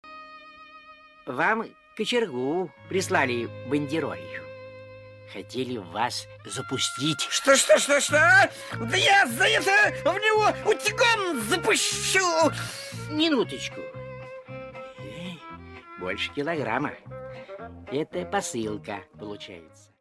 • Качество: 192, Stereo
из мультфильмов